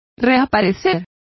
Complete with pronunciation of the translation of reappear.